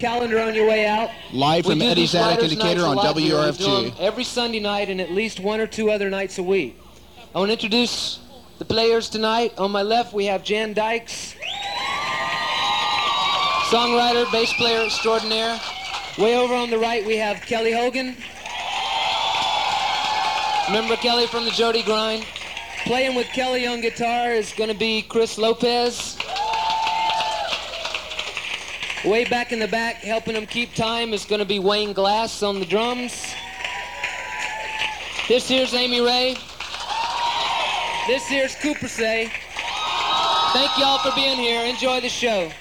songwriter showcase
(radio broadcast)